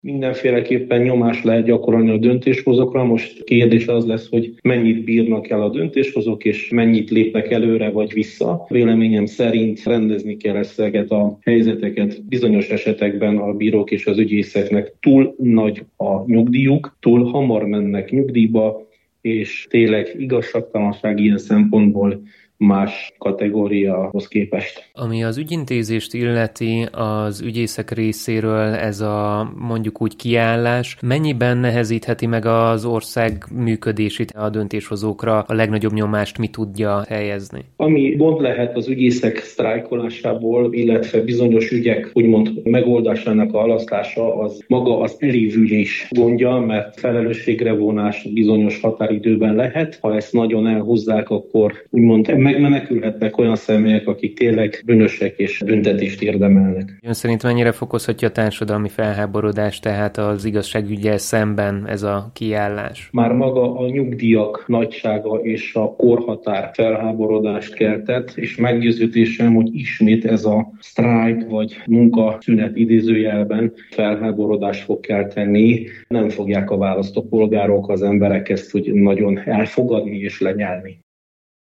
A rádiónk által megkeresett jogászok szerint a tiltakozás egyértelmű nyomásgyakorlás a kormány felé, de nehéz megítélni, hogy ez elegendő lesz-e ahhoz, hogy a döntéshozók visszavonják a jogszabályjavaslatot.